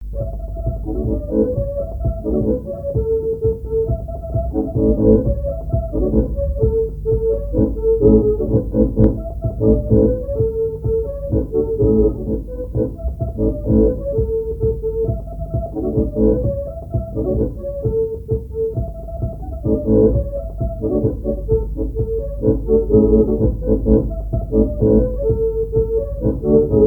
Laforte : non-référencée - V, F-** Coirault : non-référencée - 0074** Thème : 0074 - Divertissements d'adultes - Couplets à danser Résumé : A deux sous les petits les petits.
branle : courante, maraîchine
Répertoire à l'accordéon diatonique
Pièce musicale inédite